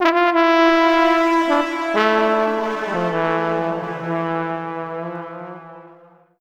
Index of /90_sSampleCDs/Zero-G - Total Drum Bass/Instruments - 3/track67 (Riffs Licks)
06-Tromboned 150bpm.wav